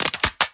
GUNMech_Insert Clip_04.wav